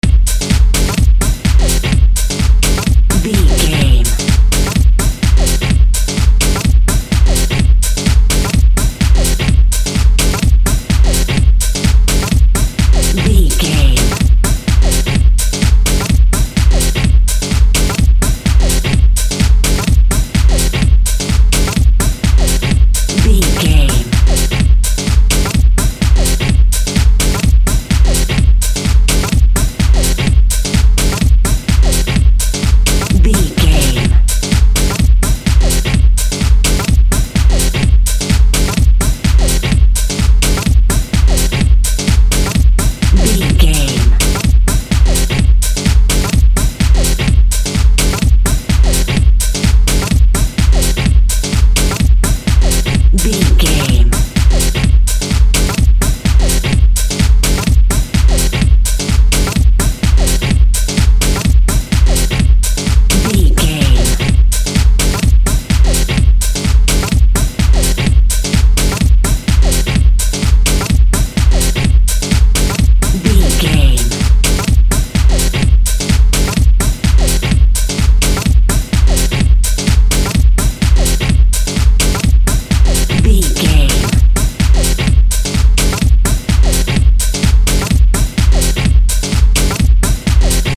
Atonal
F#
driving
energetic
futuristic
hypnotic
drum machine
synthesiser
techno
electro house
synth bass